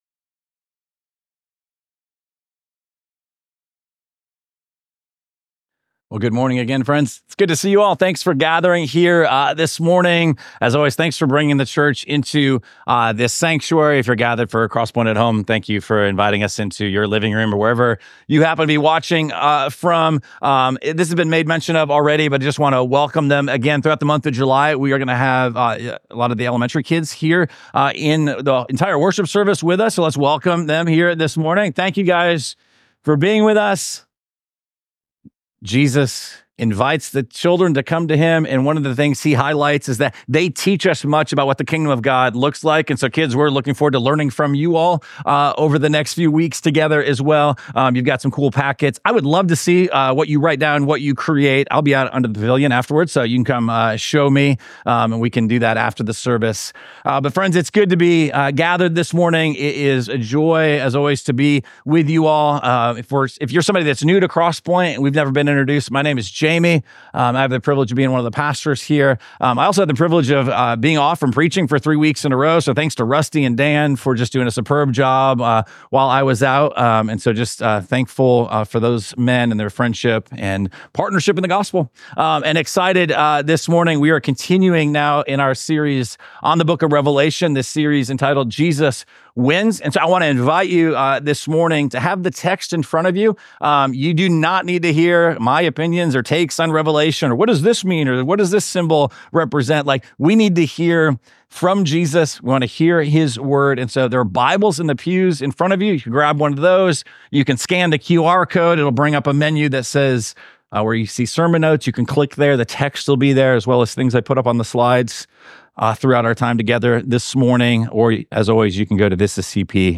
Week 12 of our series Jesus Wins: A Study of Revelation. This sermon comes from Revelation chapter 17.